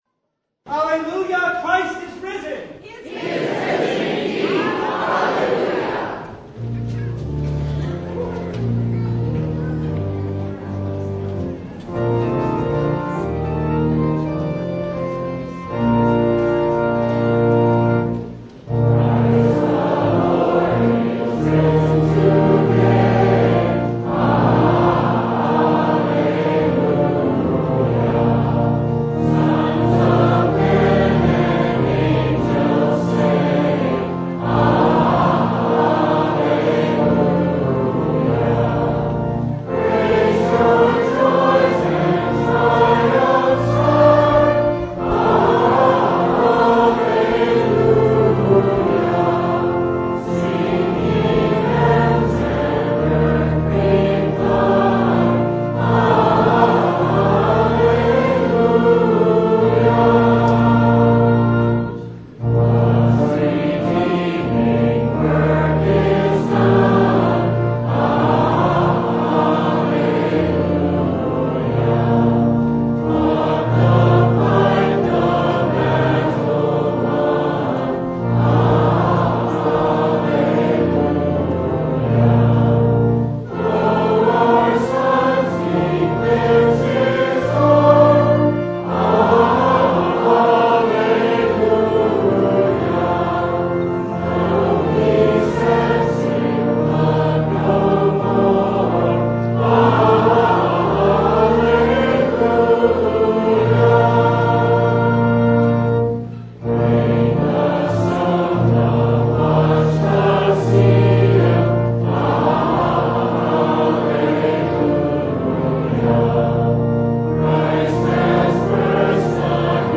Matthew 28:1-10 Service Type: Easter It’s not a dream
Download Files Bulletin Topics: Full Service « It Is Finished Jesus Is Risen!